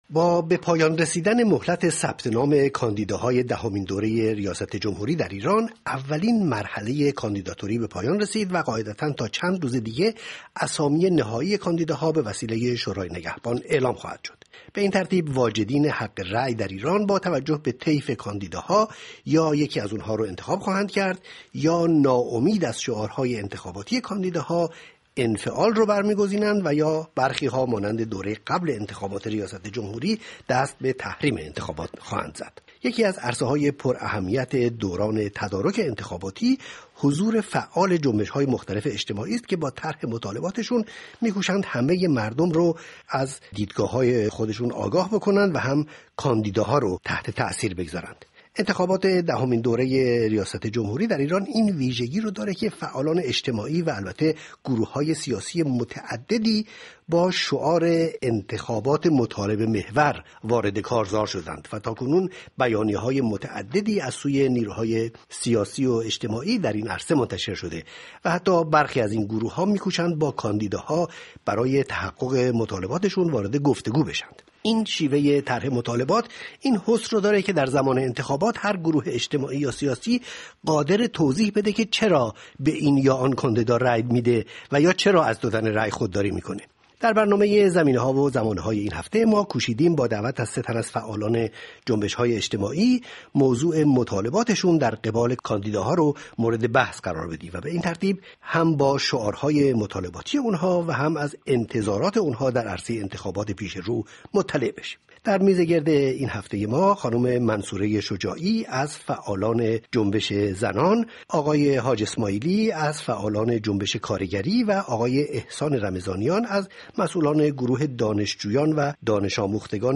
در میز گردی که به این مناسبت تدارک دیده ایم، سه تن از فعالان جنبش های اجتماعی شرکت دارند.